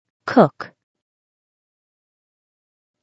Red syllables = stressed syllables
cook.mp3